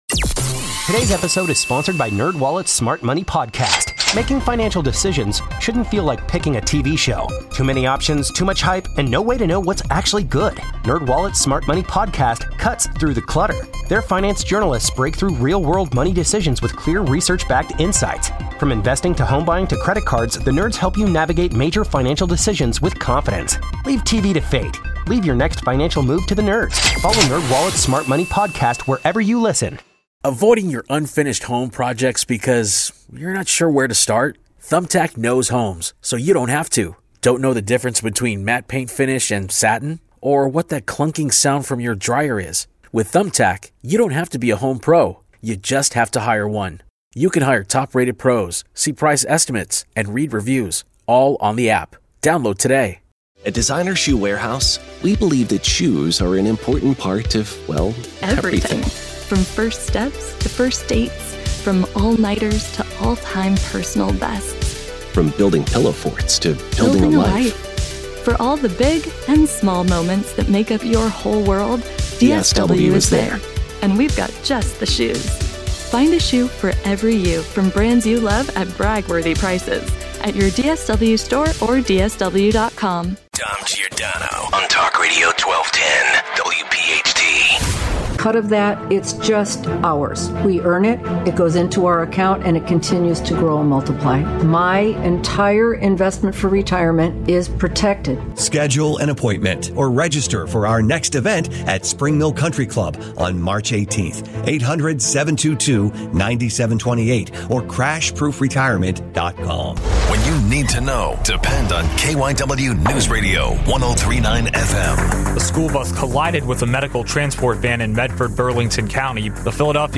Funny how things change under a new regime. 1240 - Your calls. 1250 - Baked ziti in the studio tomorrow?